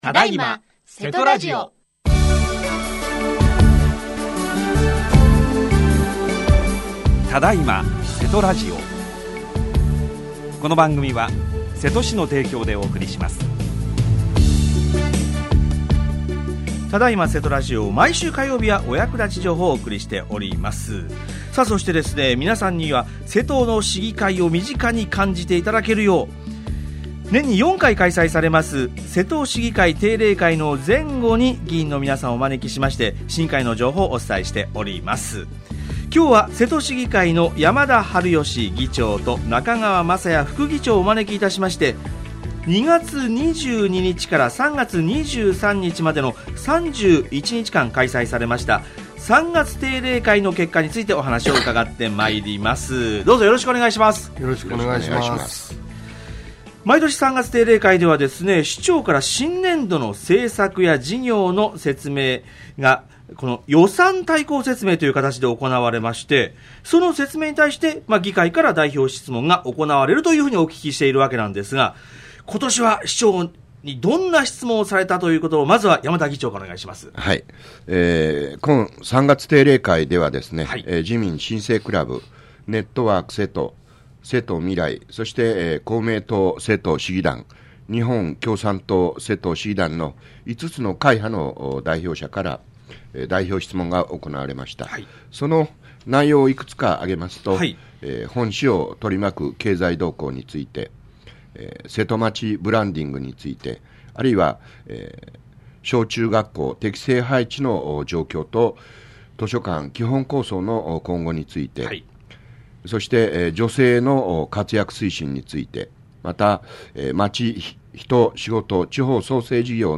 今日は、瀬戸市議会の山田治義議長と中川昌也副議長をお招きして、 ２月２２日から３月２３日までの３１日間開催された３月定例会の結果について、お話を伺いました。